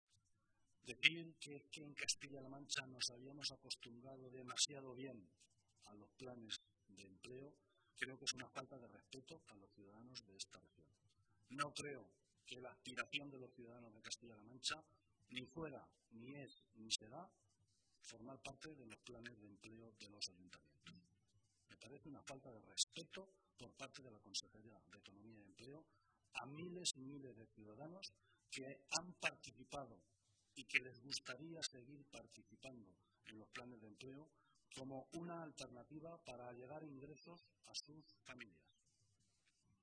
Así lo ha manifestado esta mañana, en rueda de prensa, el portavoz del Grupo Socialista, José Luis Martínez Guijarro.